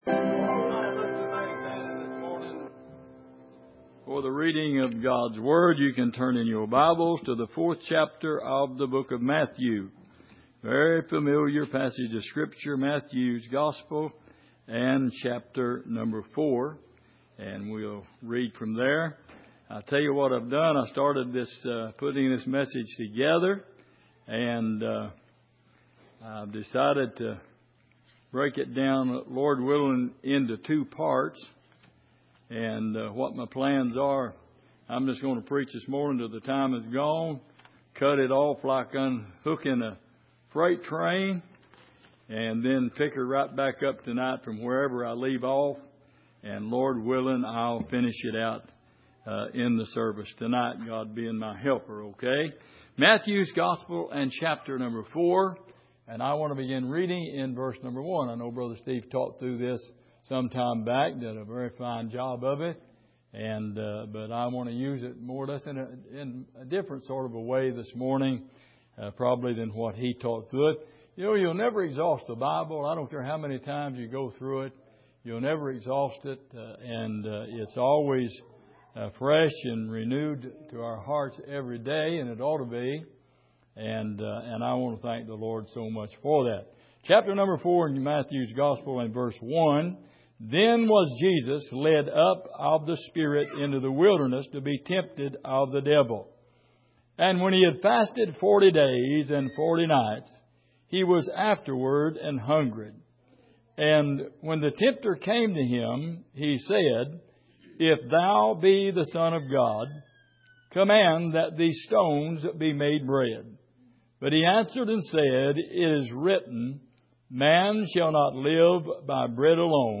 Passage: Matthew 4:1-11 Service: Sunday Morning